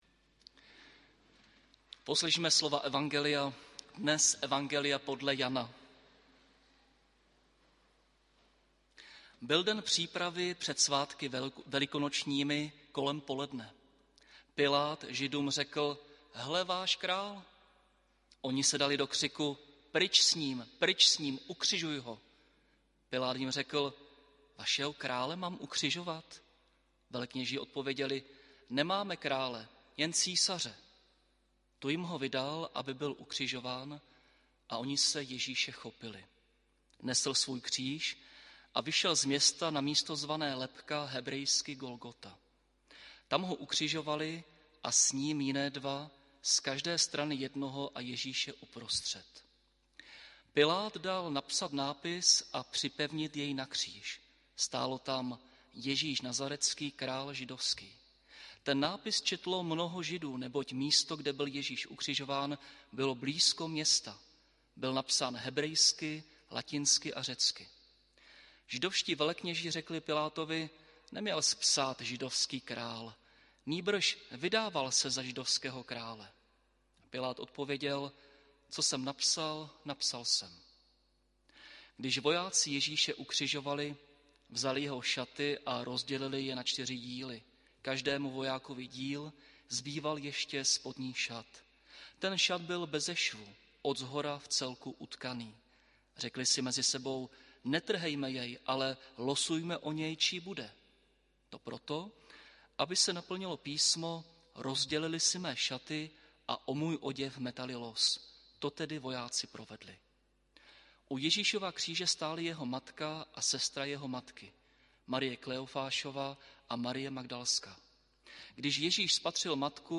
audio kázání na evangelijní texty Janovy zde